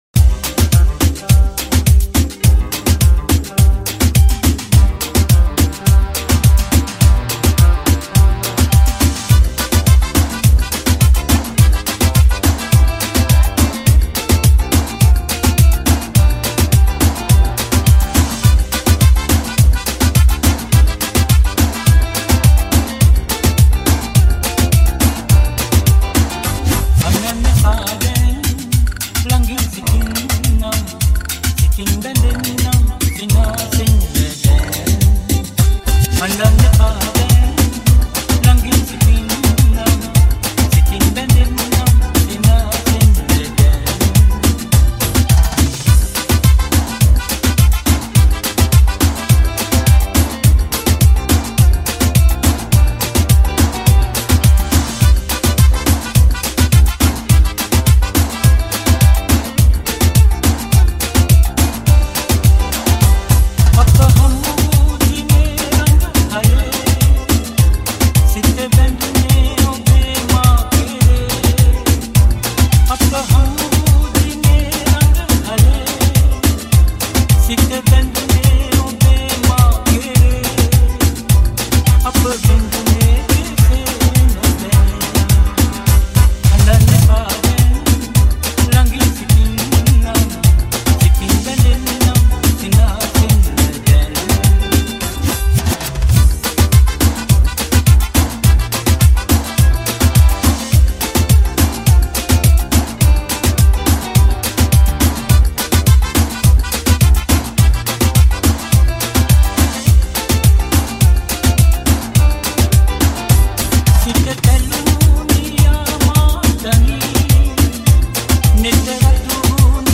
105 Bpm